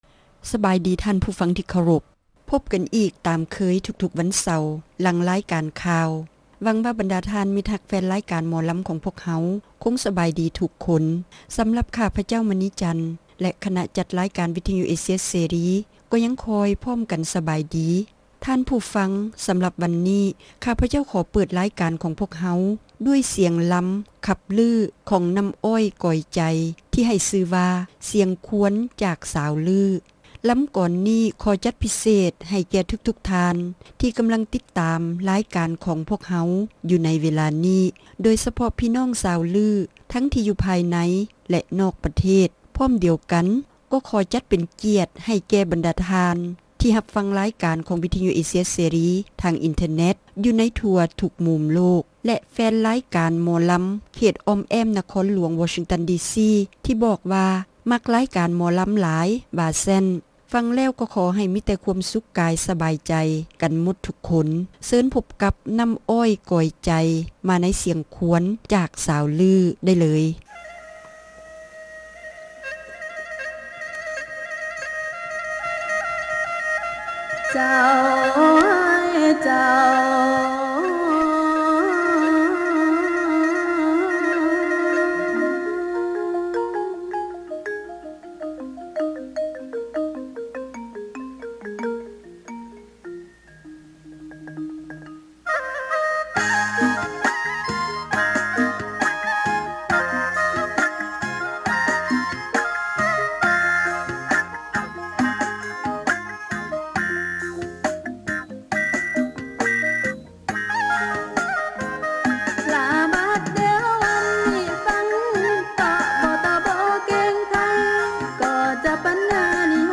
ຣາຍການໜໍລຳ